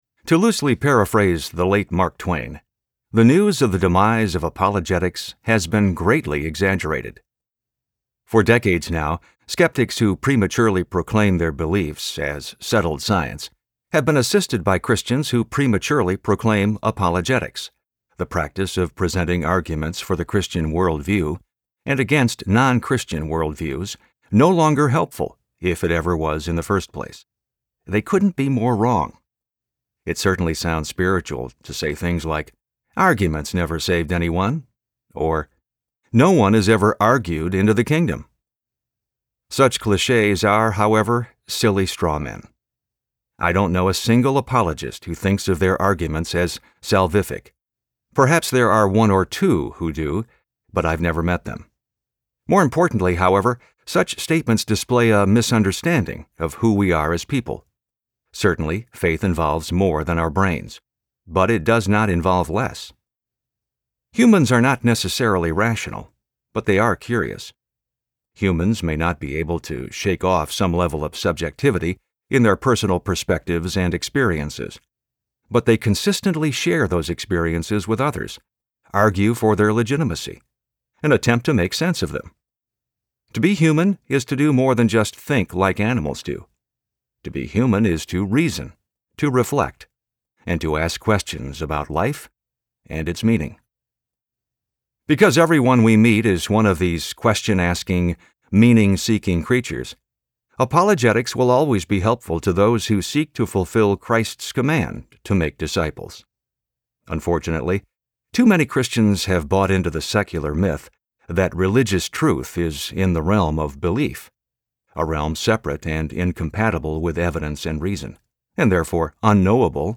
Forensic Faith Audiobook
Narrator
7.25 Hrs. – Unabridged